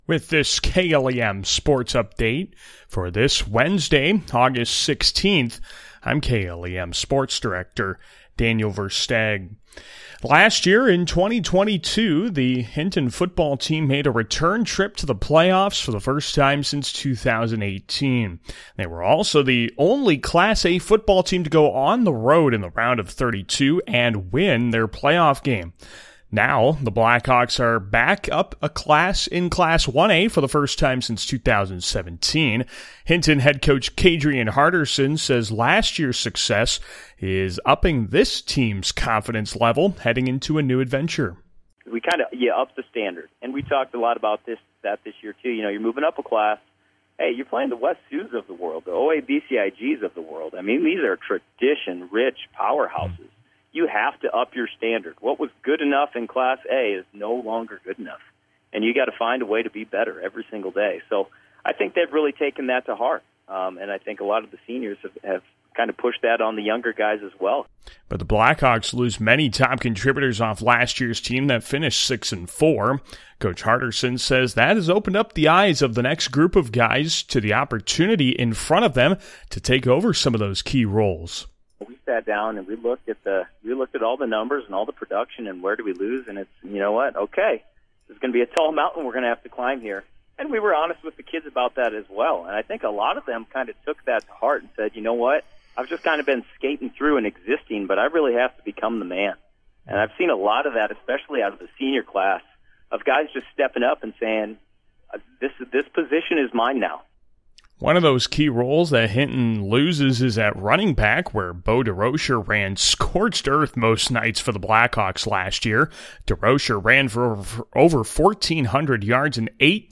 August 16, 2023 – Sportscast